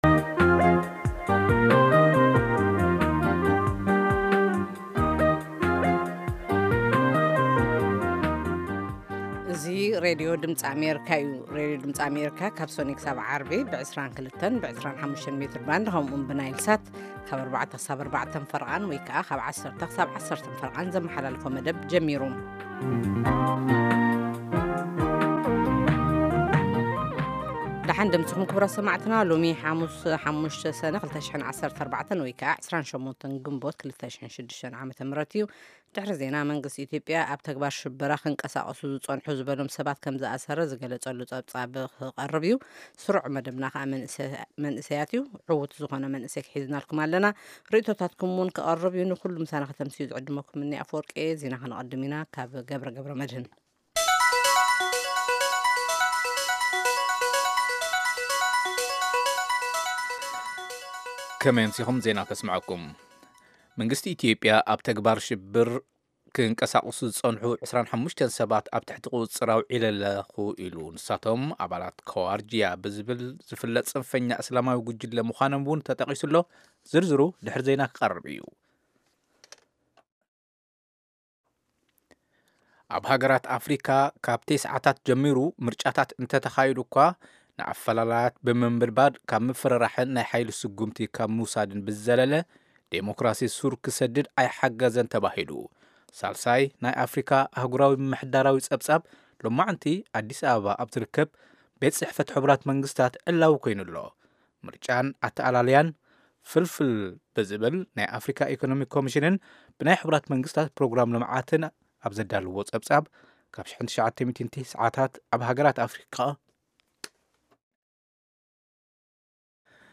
Half-hour broadcasts in Tigrigna of news, interviews with newsmakers, features about culture, health, youth, politics, agriculture, development and sports on Monday through Friday evenings at 10:00 in Ethiopia and Eritrea.